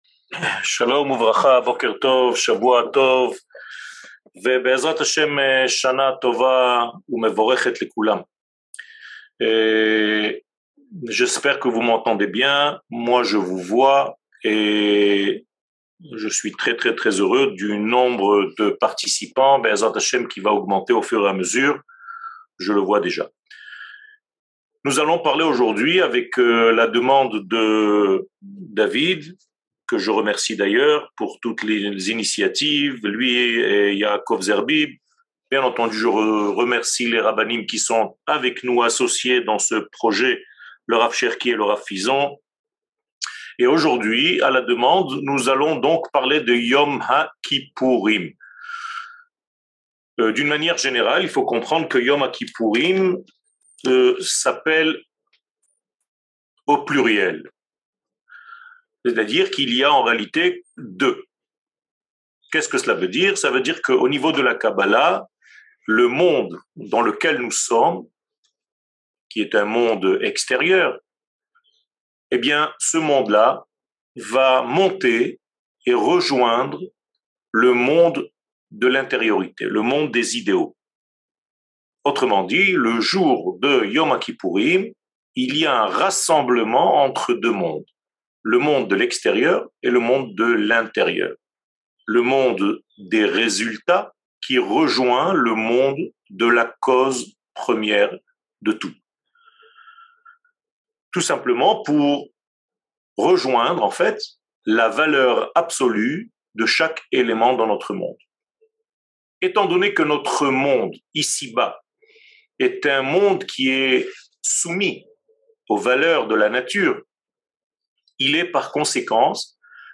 Kippour, le jour du “lâcher prise” 01:03:56 Kippour, le jour du “lâcher prise” שיעור מ 18 ספטמבר 2022 01H 03MIN הורדה בקובץ אודיו MP3 (58.53 Mo) הורדה בקובץ וידאו MP4 (145.39 Mo) TAGS : שיעורים קצרים